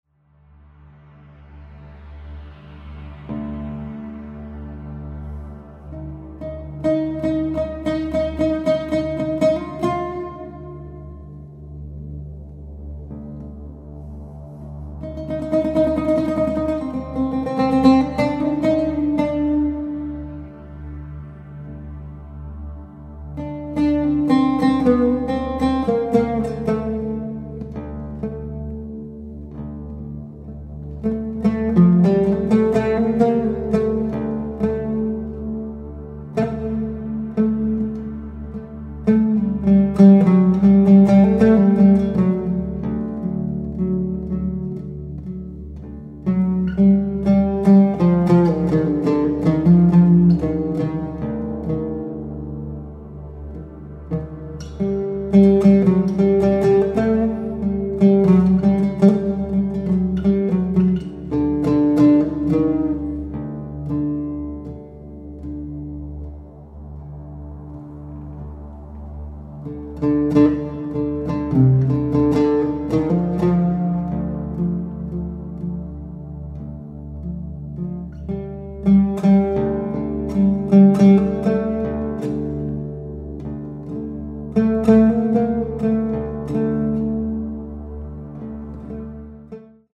composer, lute & oud player from Japan
Ambient Music , Oud